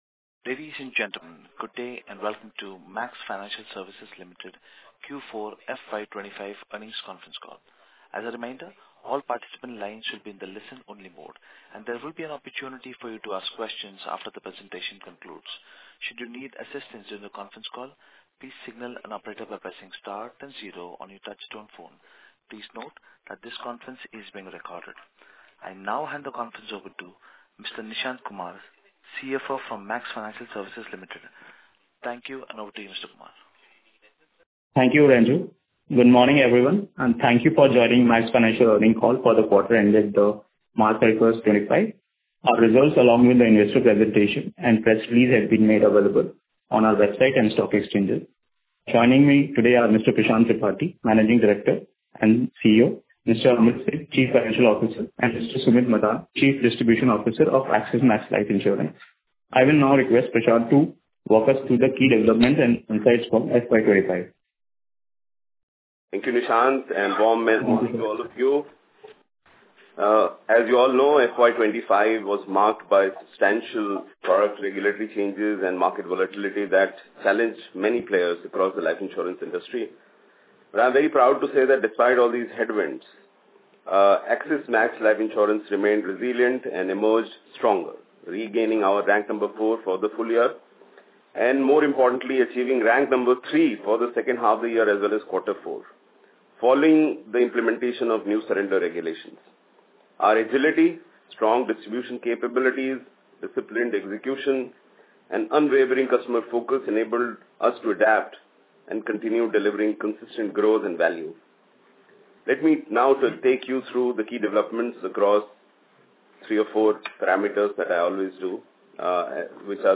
Concalls
mfsl-earnings-call-q4fy25.mp3